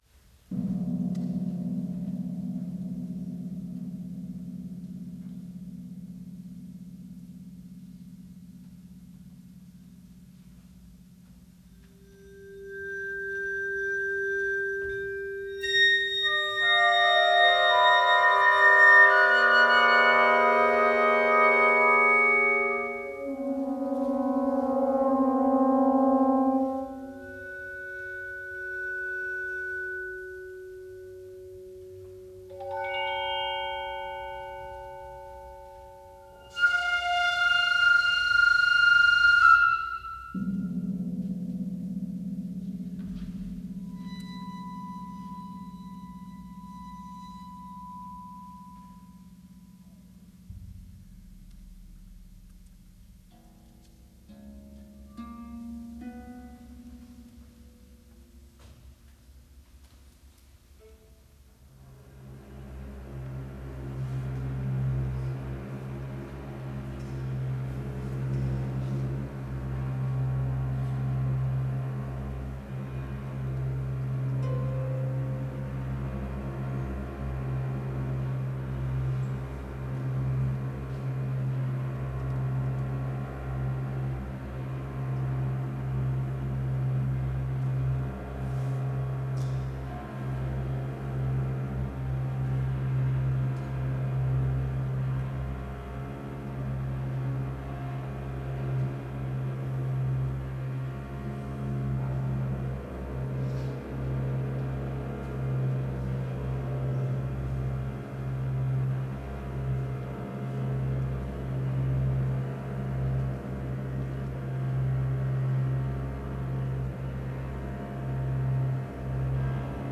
full orchestra Score Program Notes Listen